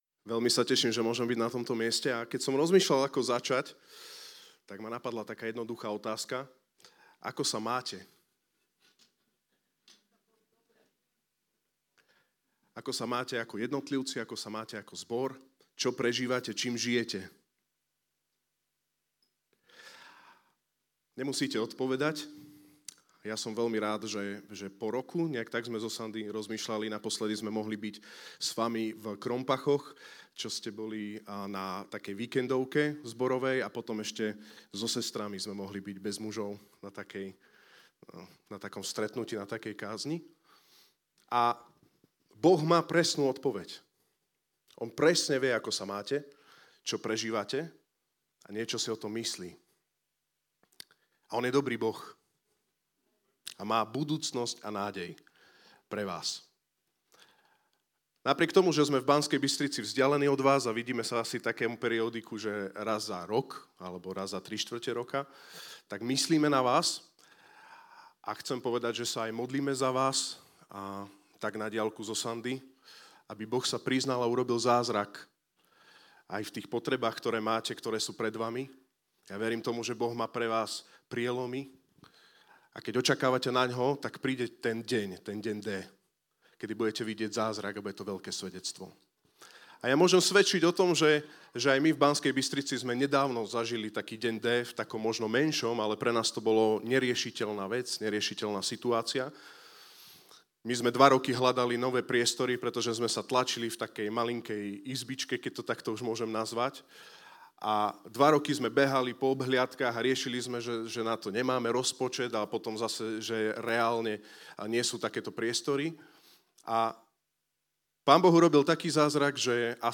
To je témou dnešnej kázne.